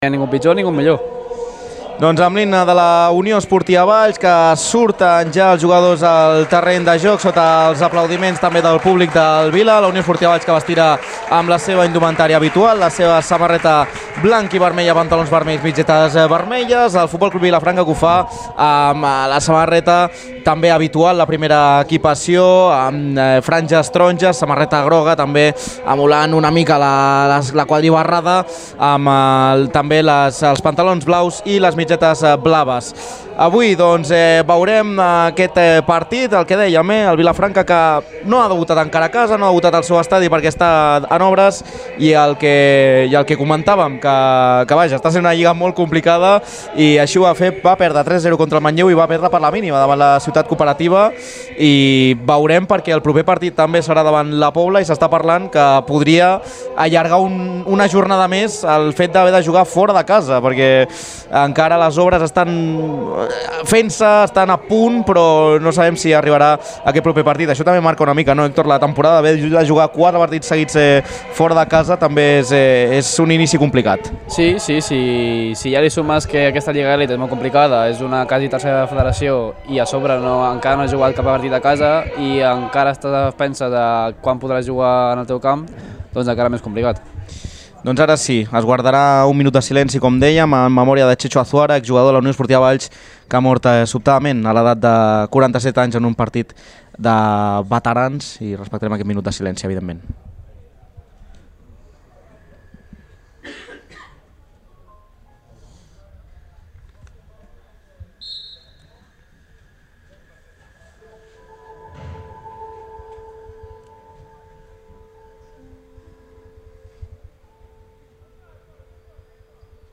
La Transmissió: UE Valls – FC Vilafranca (2-1) | Ràdio Ciutat de Valls